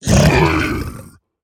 Minecraft Version Minecraft Version 25w18a Latest Release | Latest Snapshot 25w18a / assets / minecraft / sounds / mob / piglin_brute / death1.ogg Compare With Compare With Latest Release | Latest Snapshot
death1.ogg